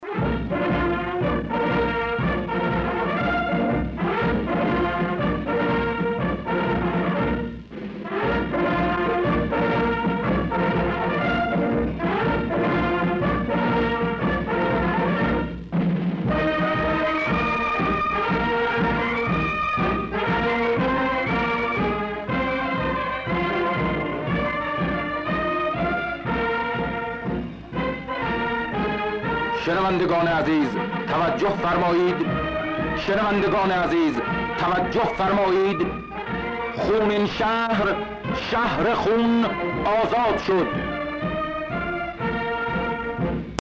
اخبار درباره آزادسازي خرمشهر / خونین شهر آزاد شد